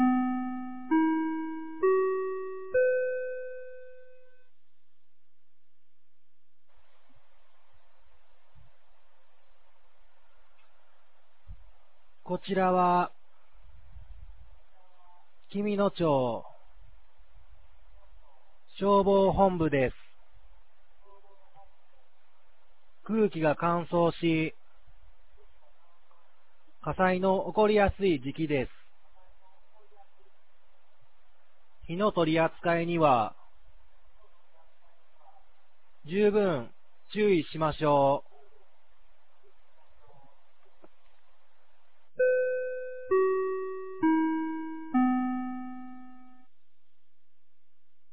2024年01月06日 16時00分に、紀美野町より全地区へ放送がありました。